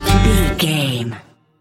Ionian/Major
banjo
violin
double bass
acoustic guitar
Pop Country
country rock
bluegrass
happy
uplifting
driving
high energy